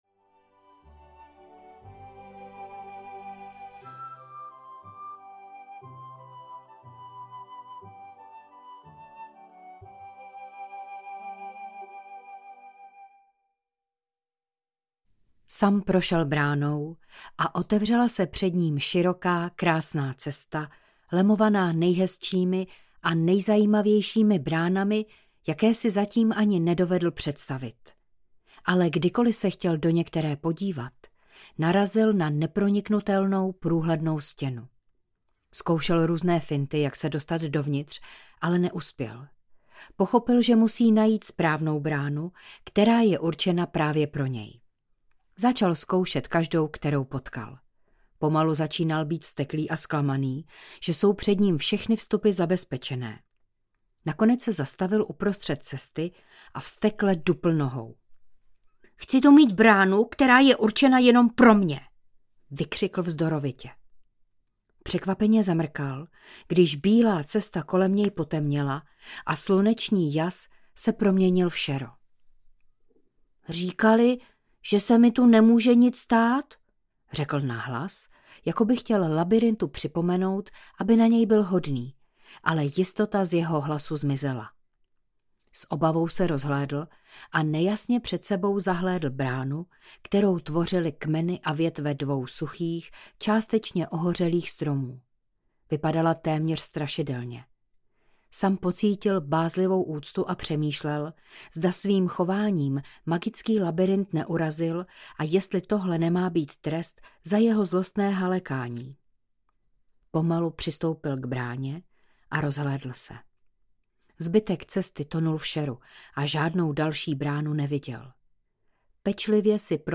Průvodce ztracených audiokniha
Ukázka z knihy